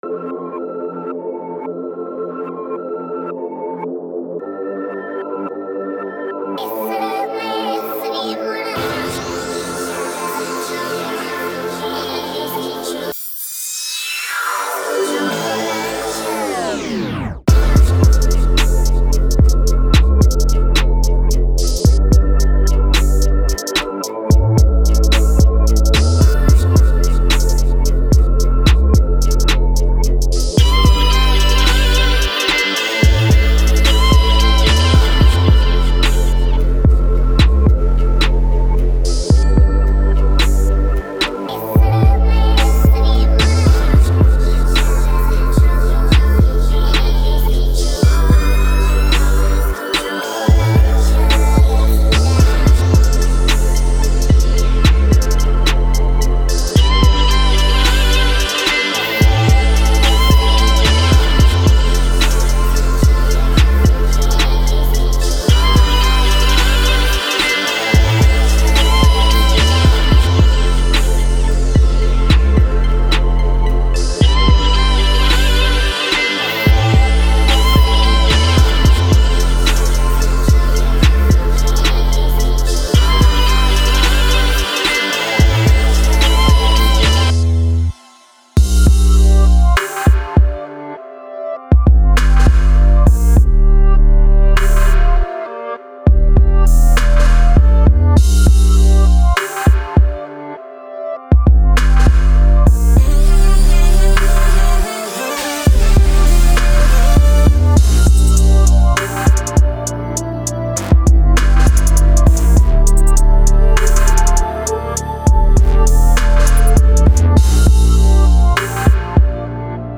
FX / 电影Hip Hop
对于希望简单拖放的用户，“湿”文件包含音频演示中听到的所有效果处理。